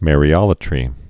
(mârē-ŏlə-trē)